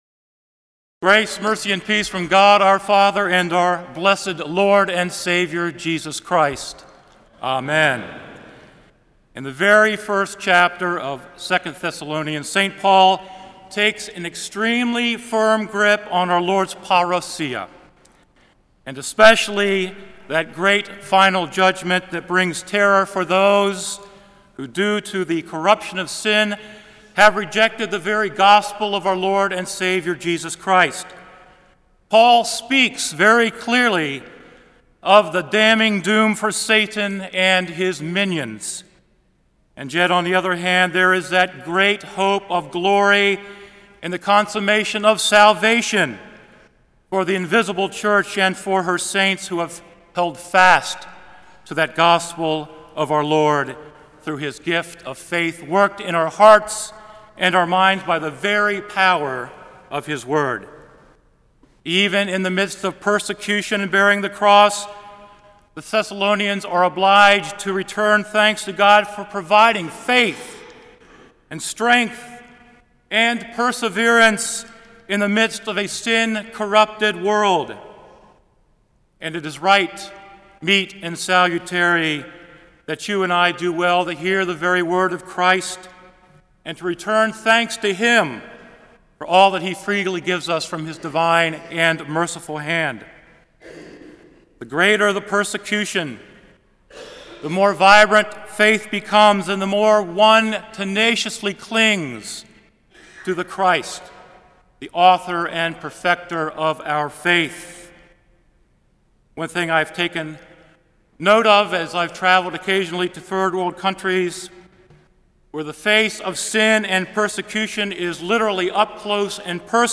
Kramer Chapel Sermon - November 15, 2007